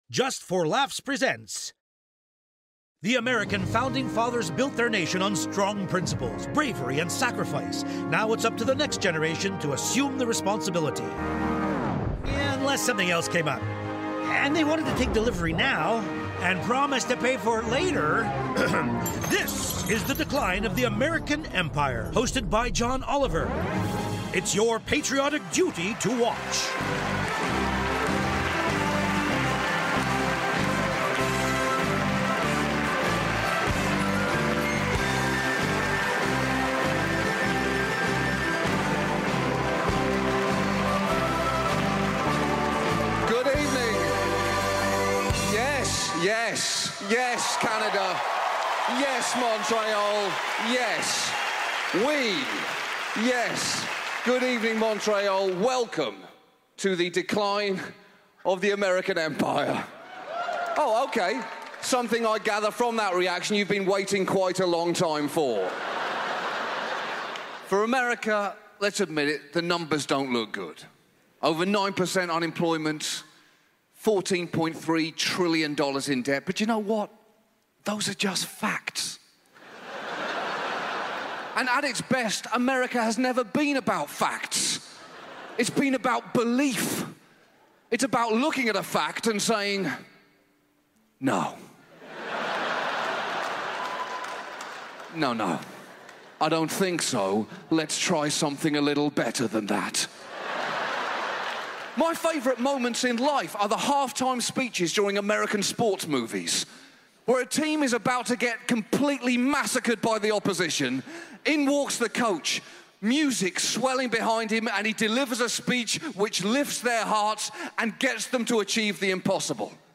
Before the first laugh even lands, you should know this: every episode of The Comedy Room places all advertisements right at the beginning, so once the show truly starts, nothing interrupts the flow, the rhythm, or the feeling.